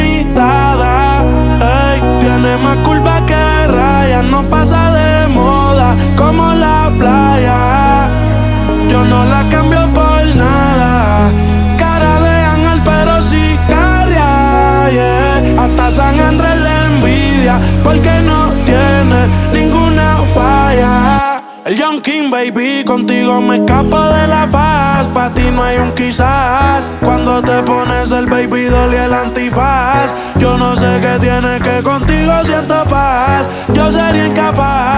Жанр: Латиноамериканская музыка
# Latino